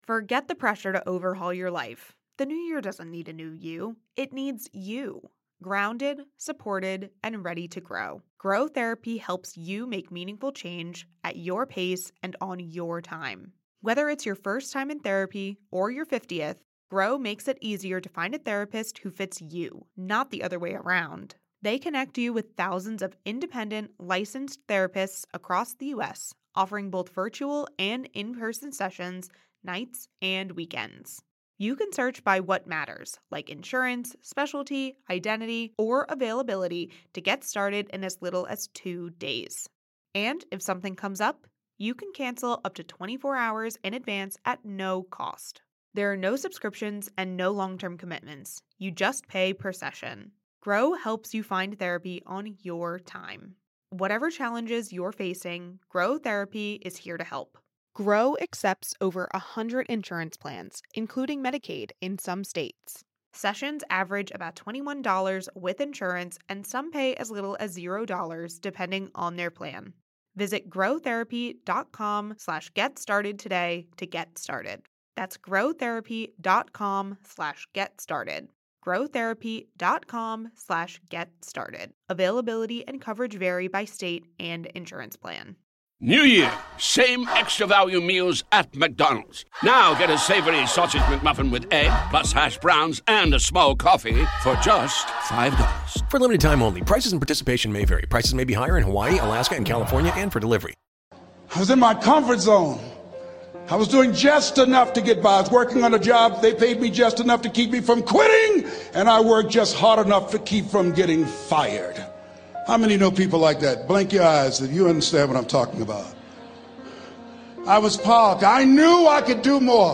Speaker: Les Brown